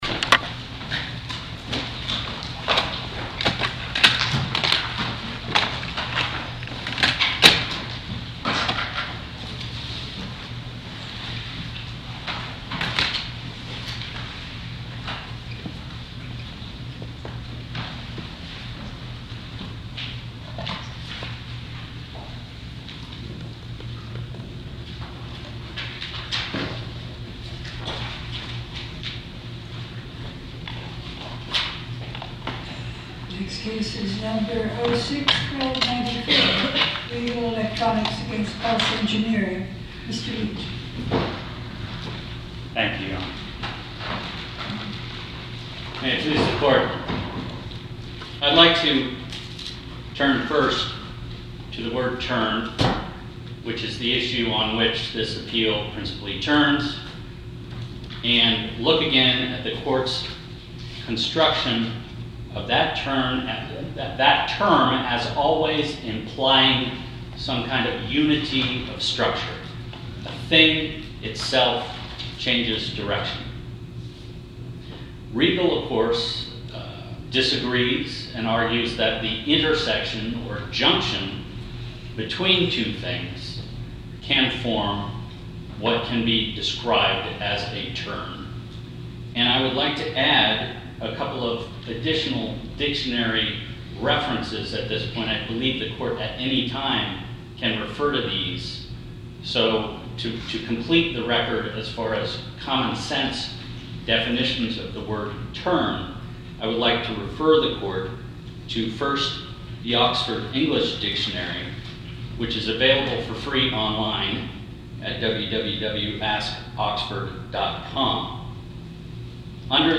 Oral argument audio posted: Regal Electronics v Pulse Engineer (mp3) Appeal Number: 2006-1294 To listen to more oral argument recordings, follow this link: Listen To Oral Arguments.